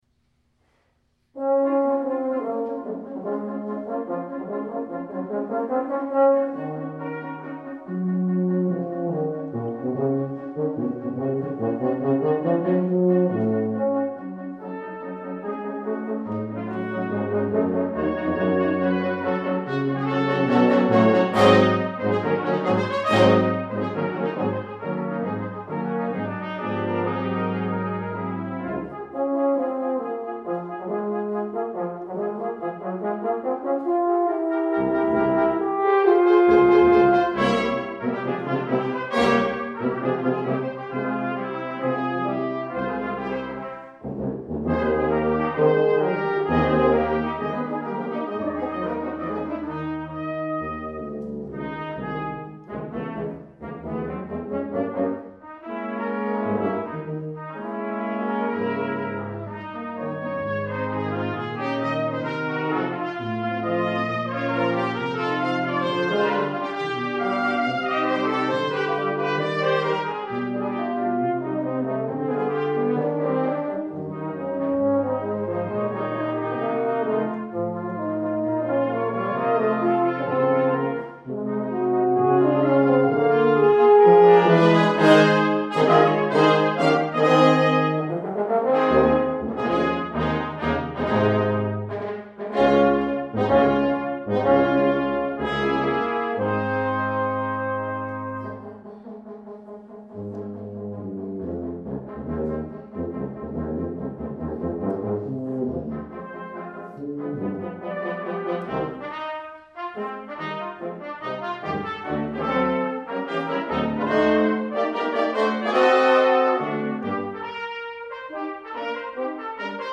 Trumpet
Horn
Euphonium
Tuba
December 2009 Recital: